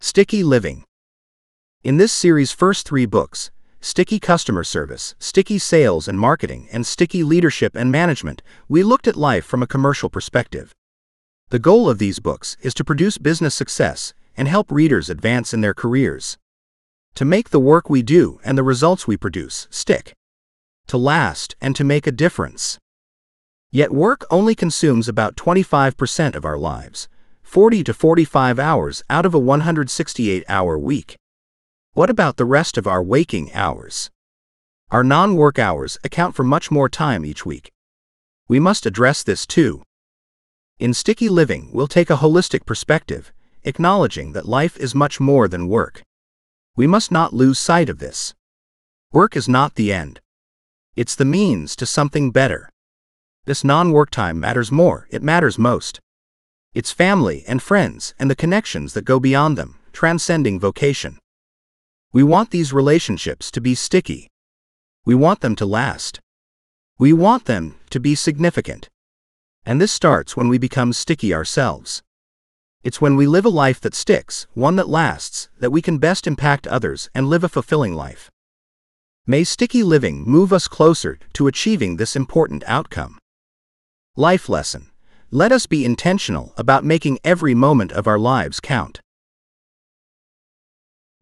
Sticky-Living-audiobook-sample.mp3